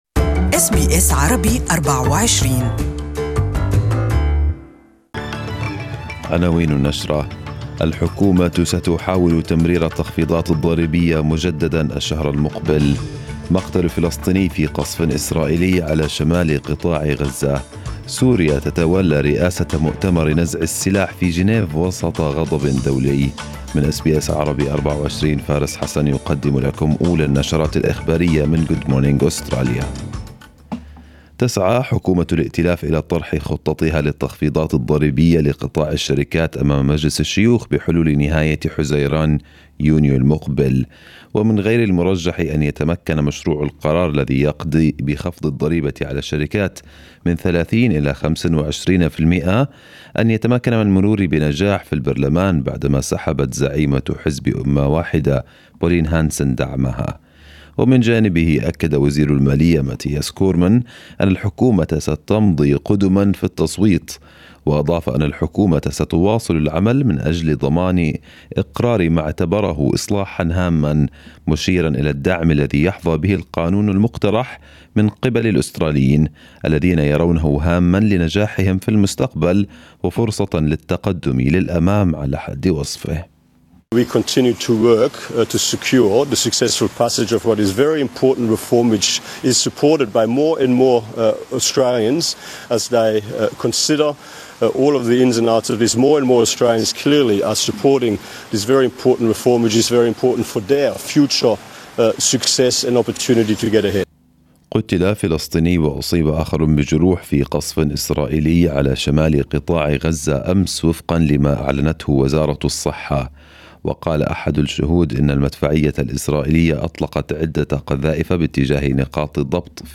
Arabic News Bulletin 29/05/2018